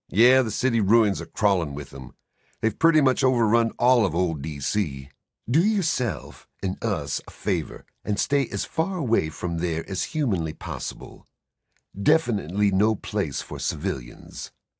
Category: Fallout 3 audio dialogues Du kannst diese Datei nicht überschreiben.